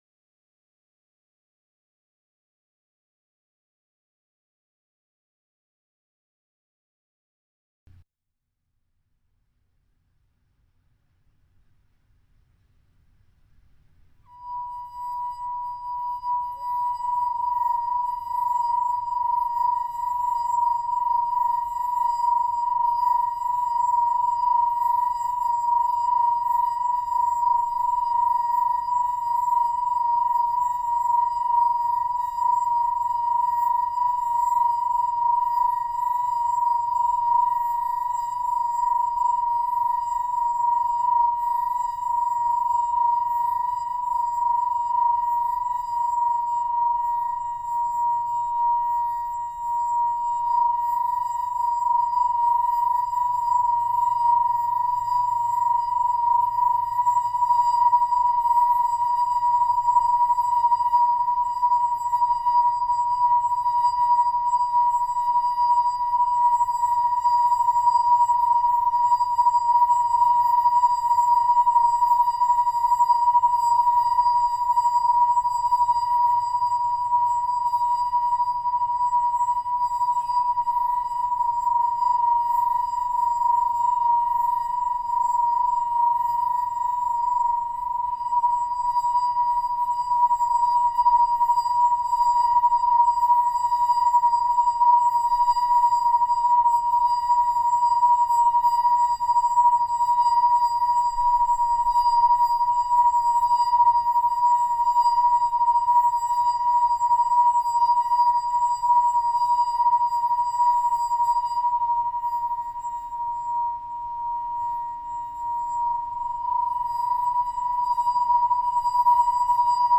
" for tuned wine glasses.